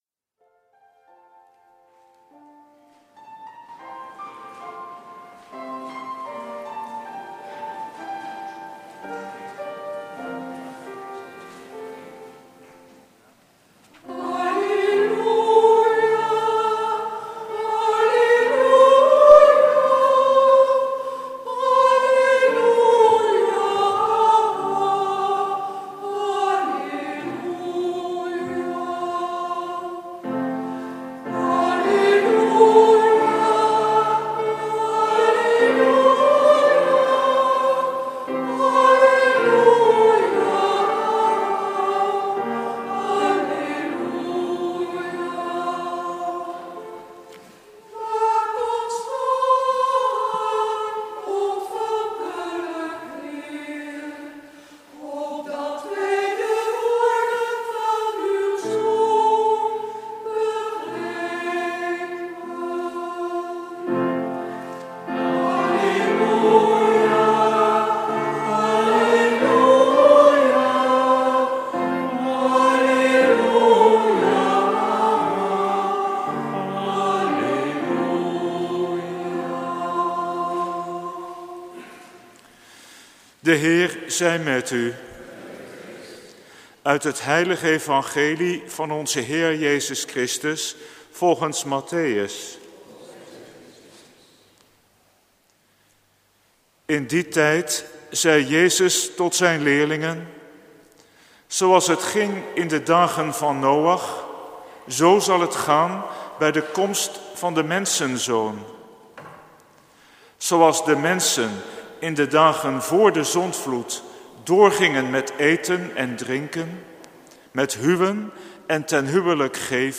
Preek 1e zondag van de Advent, jaar A, 30 november/1 december 2013 | Hagenpreken